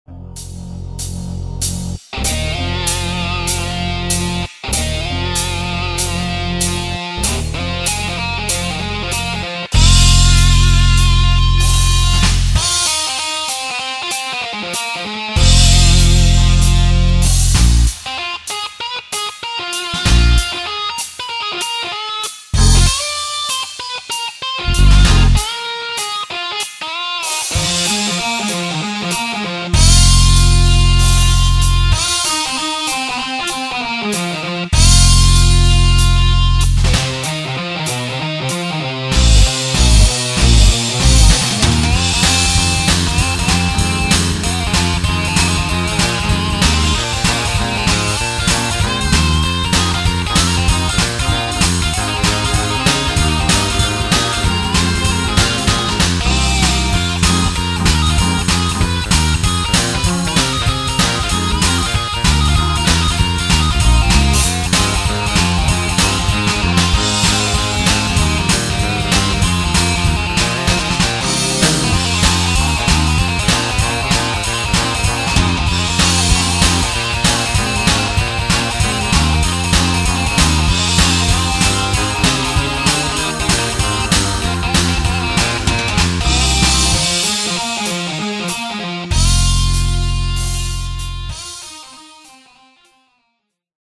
Rock 70's